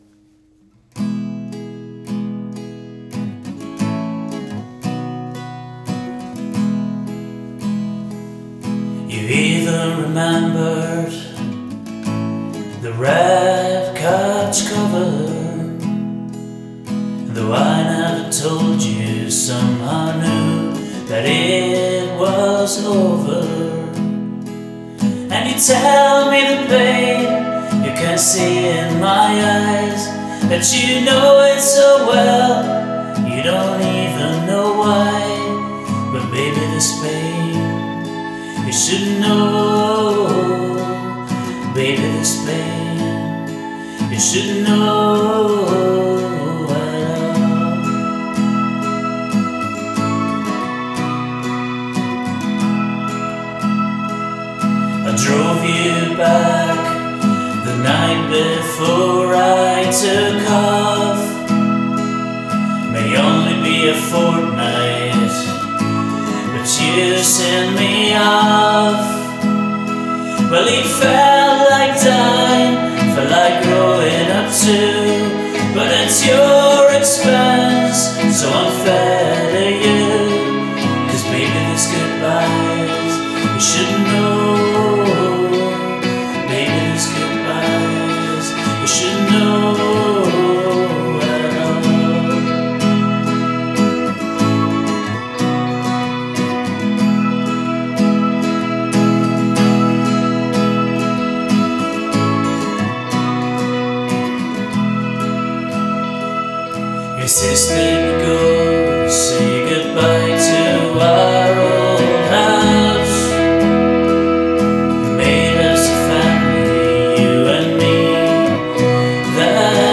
vocals, guitars, bass, keyboards